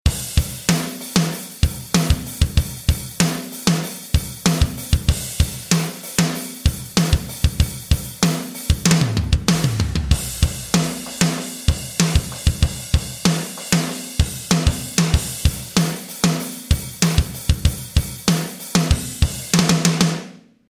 Powerful, studio-grade rock drums perfect for energetic, driving modern rock or metal. Captured with vintage mics and processed through analog gear for desert smack.
Snare Low (Drums)
Recorded at Fireside Sound in Joshua Tree, California during the making of Silverstein's albums Antibloom and Pink Moon.
dd-snare-low-drums.Cy0zVXr5.mp3